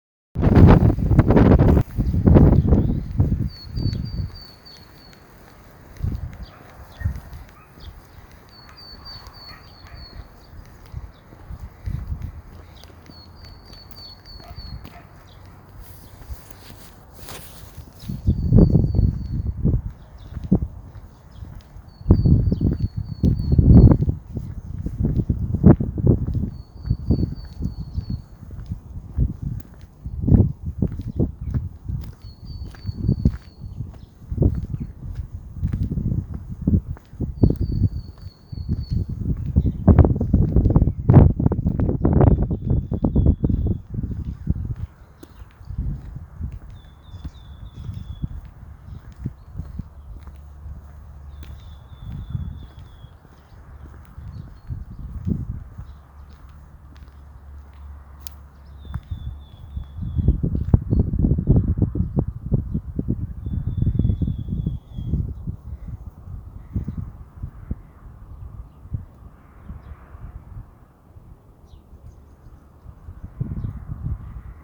поползень, Sitta europaea
Ziņotāja saglabāts vietas nosaukumsLīči pie N
СтатусСлышен голос, крики